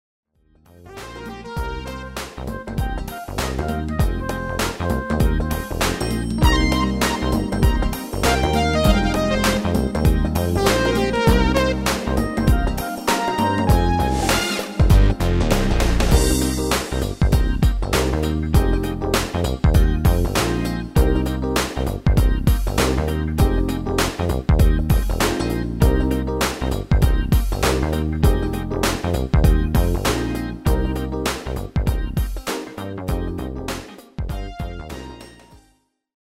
Genre: Dance / Techno / HipHop / Jump
- Géén tekst
- Géén vocal harmony tracks